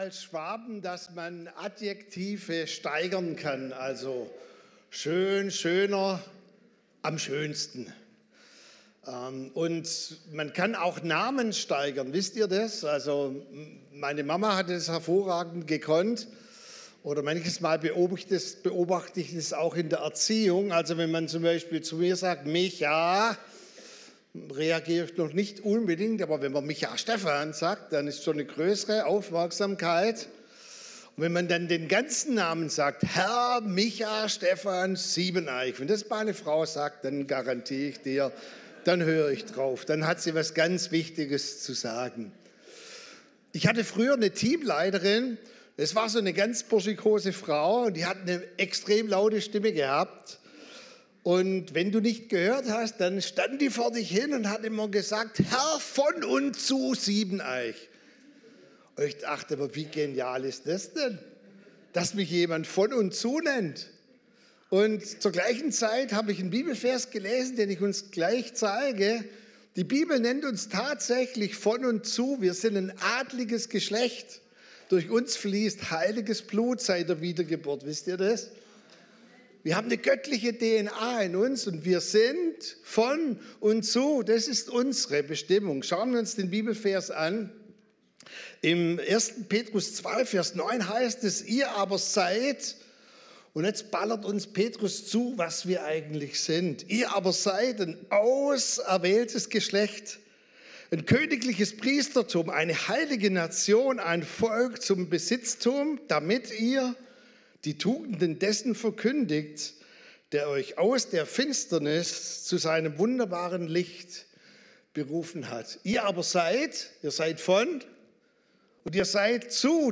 Wöchentliche Predigten des Christlichen Gemeindezentrums Albershausen